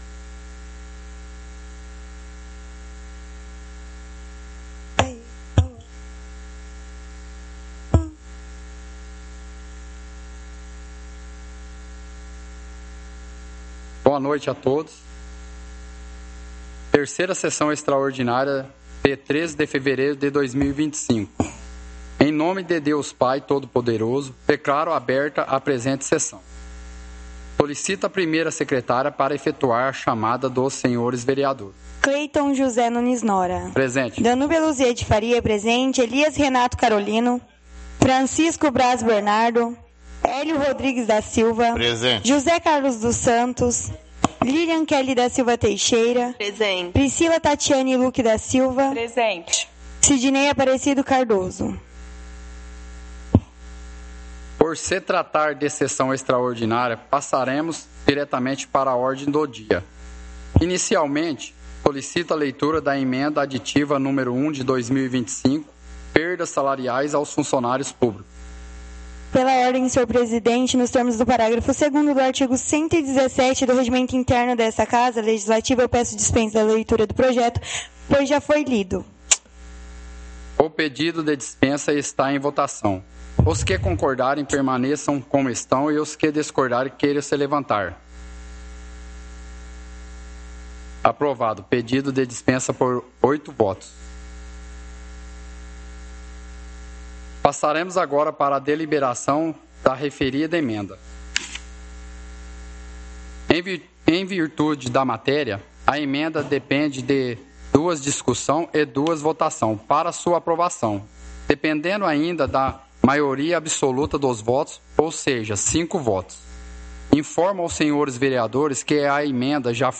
Áudio da 3ª Sessão Extraordinária – 13/02/2025